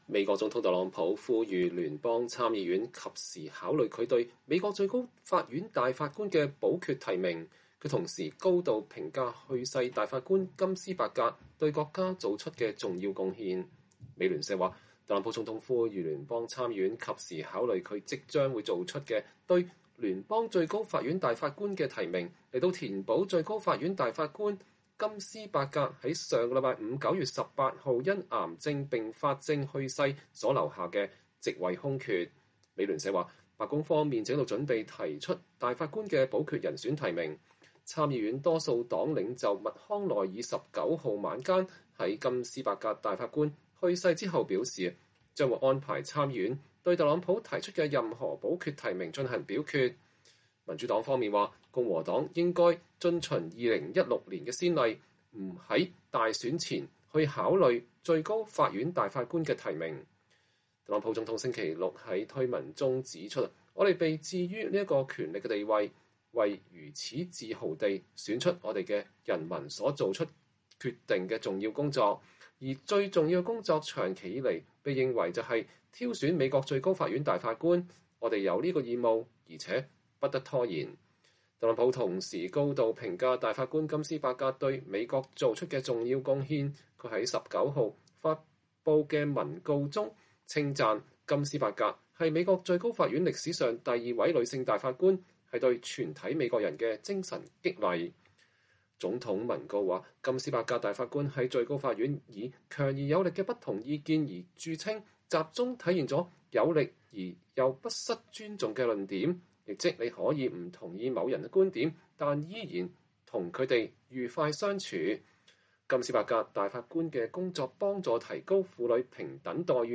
美國總統特朗普9月18日晚間就最高法院大法官金斯伯格的去世對記者發表講話。